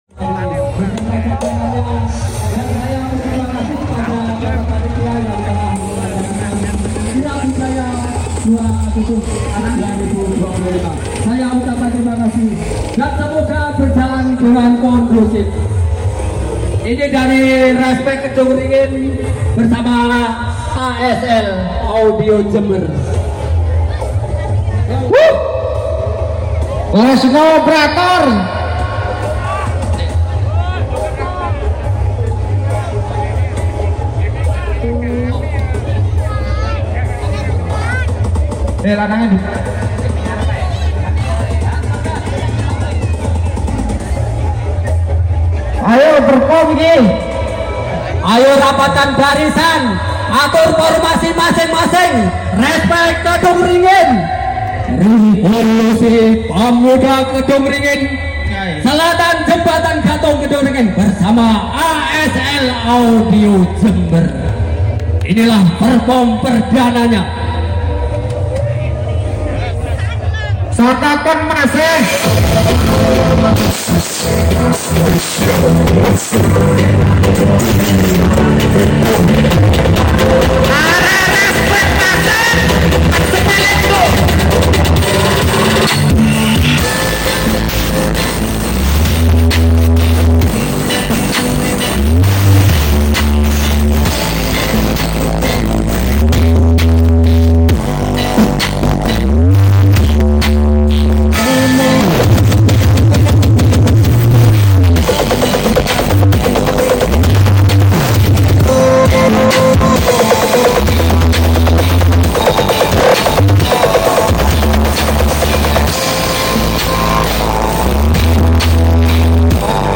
Karnaval Desa Kedungringin Muncar ASL sound effects free download